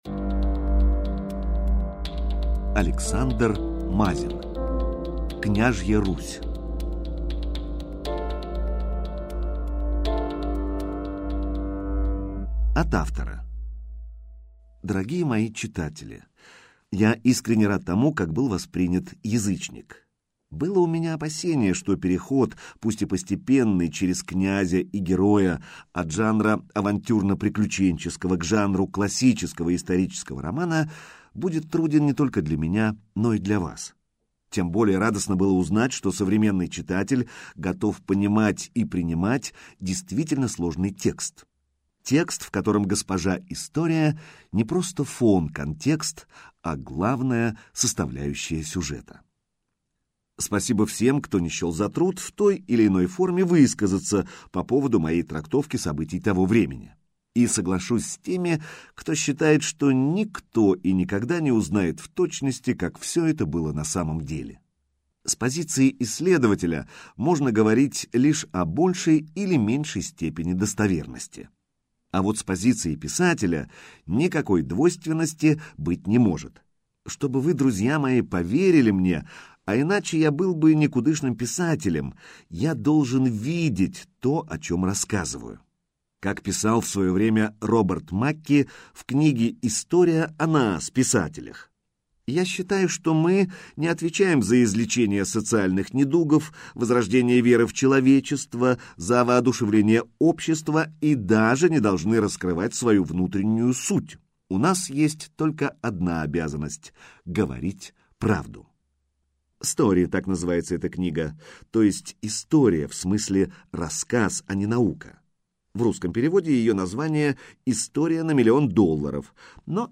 Аудиокнига Княжья Русь | Библиотека аудиокниг